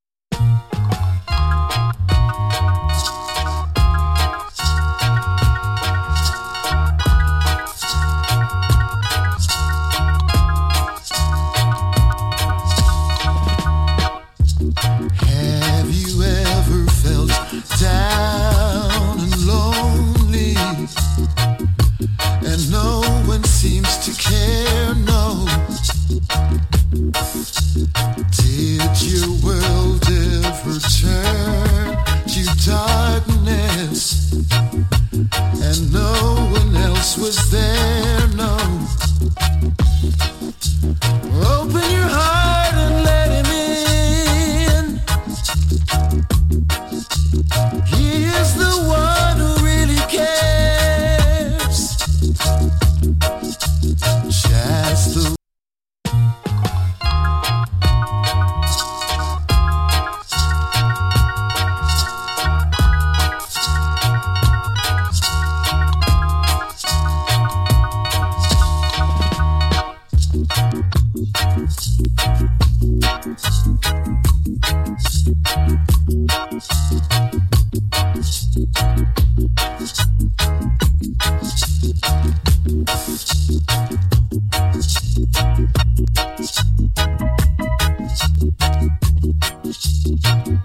KILLER ROOTS VOCAL !